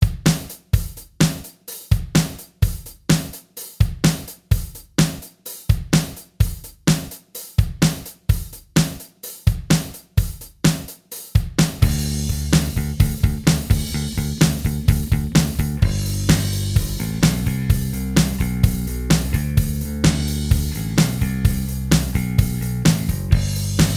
Minus All Guitars Rock 3:34 Buy £1.50